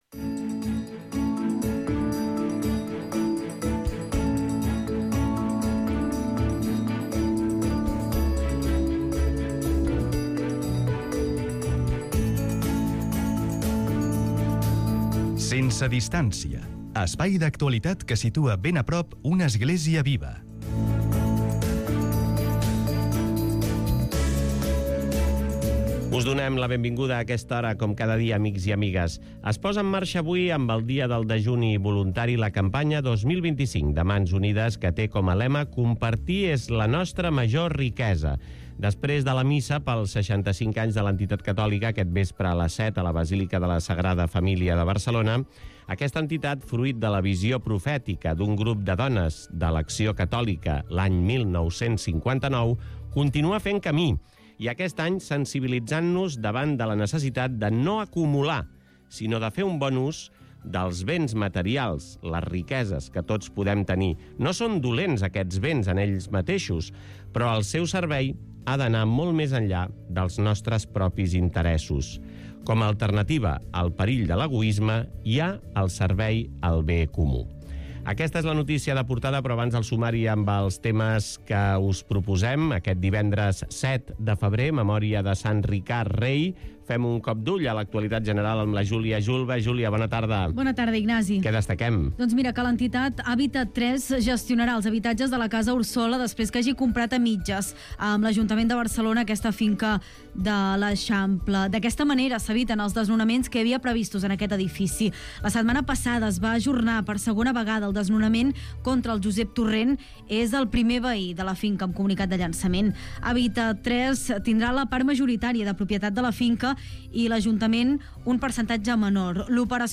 Aquest programa de radio es va emetre el passat divendres 7 de febrer a les 14:40h prop de la jornada de Santa Bakhita a Radio Estel.